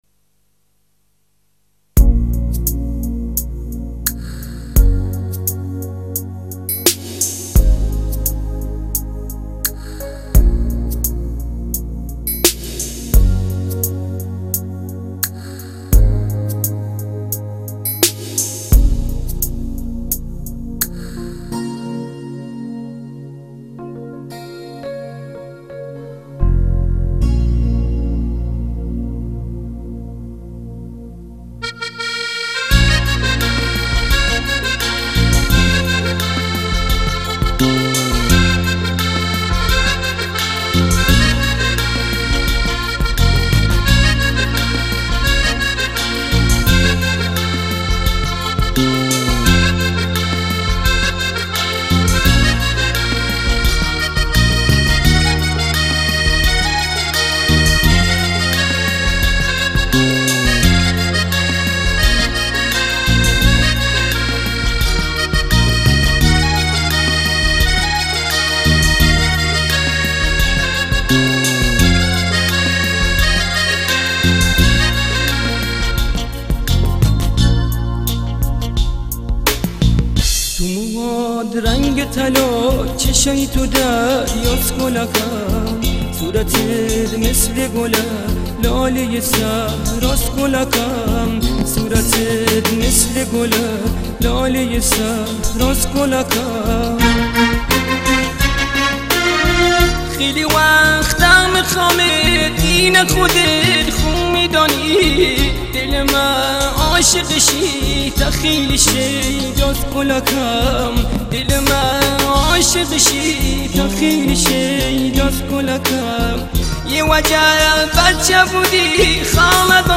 ترانه کردی